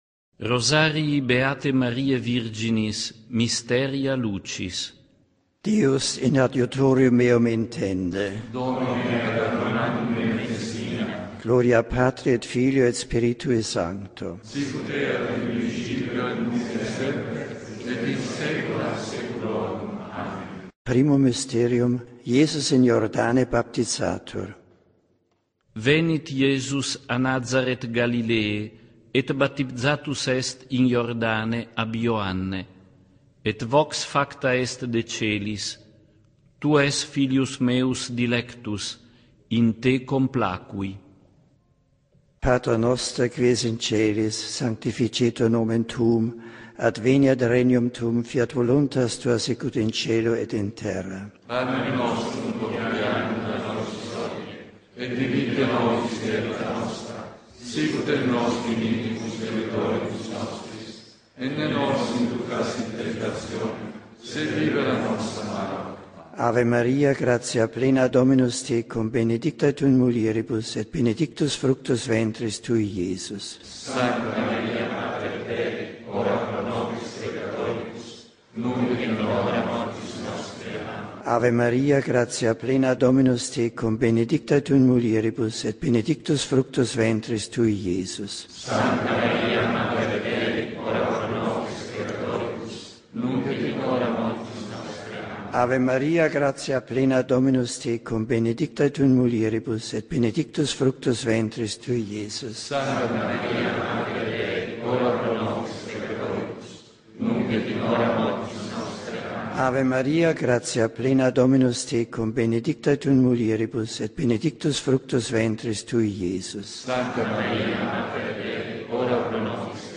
Mysteria Lucis (audio) Trascription of the Luminous Mysteries Rosary in Latin as recited by Pope Emeritus Benedict XVI
Rosario-in-Latino-di-Benedetto-XVI-Misteri-Luminosi.mp3